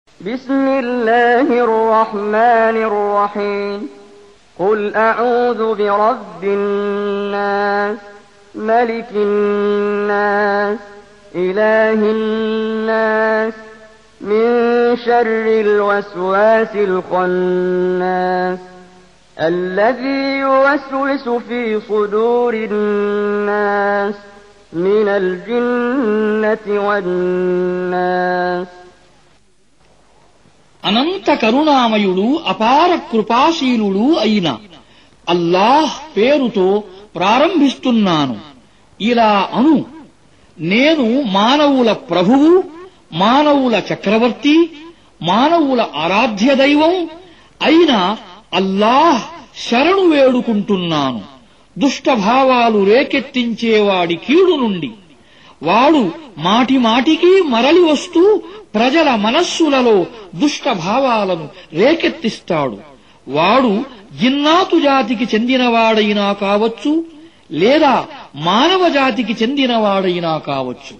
Surah Repeating تكرار السورة Download Surah حمّل السورة Reciting Mutarjamah Translation Audio for 114. Surah An-N�s سورة النّاس N.B *Surah Includes Al-Basmalah Reciters Sequents تتابع التلاوات Reciters Repeats تكرار التلاوات